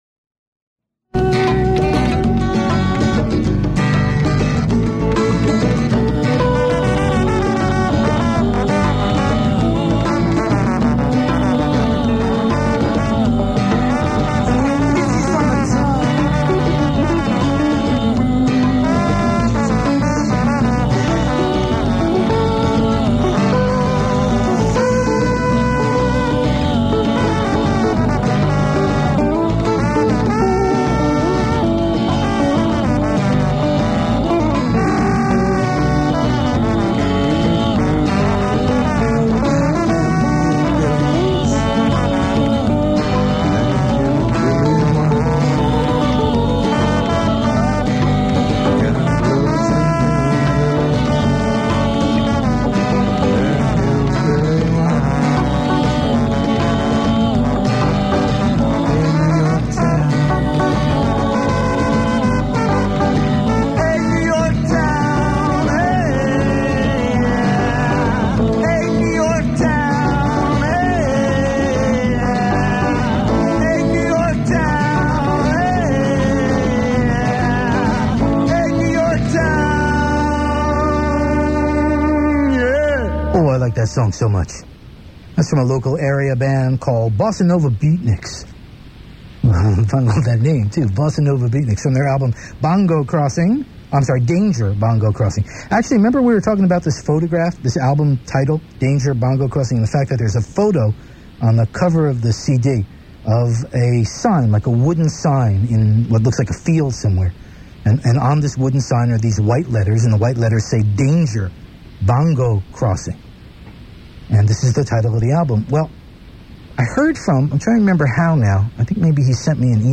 (sound is a bit fuzzy from being recorded off a small radio)